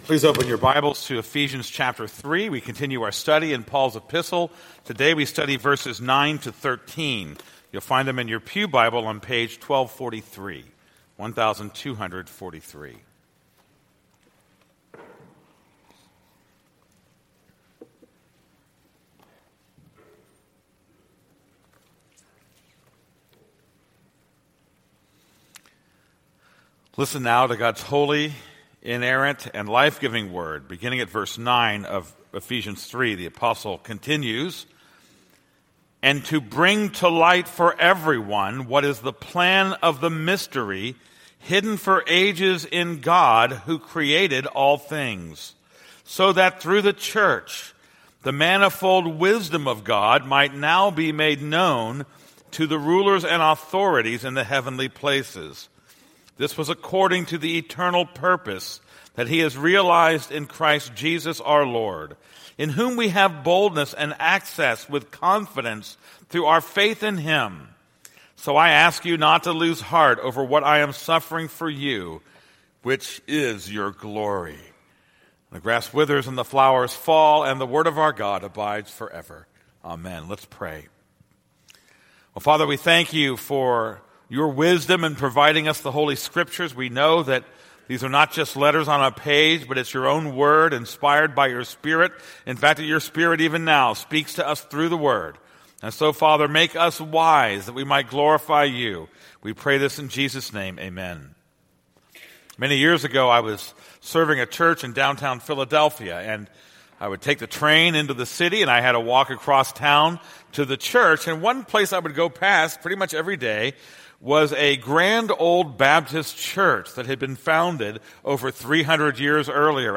This is a sermon on Ephesians 3:9-13.